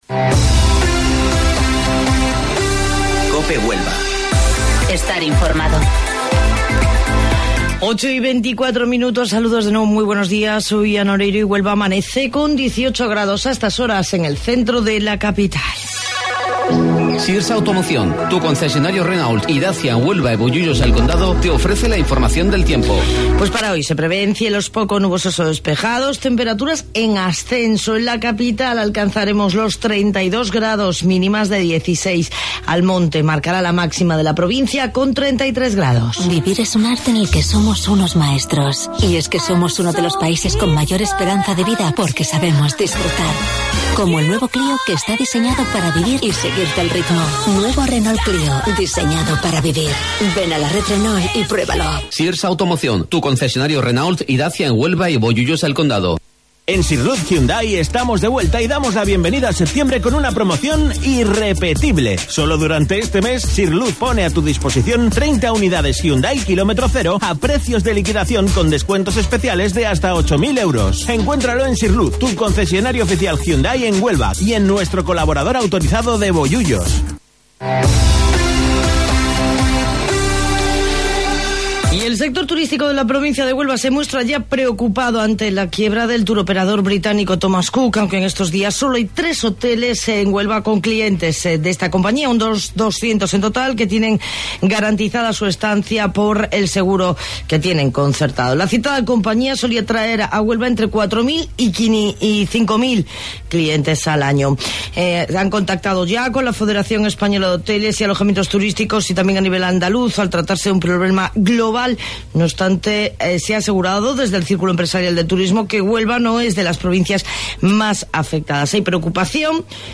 AUDIO: Informativo Local 08:25 del 24 de Septiembre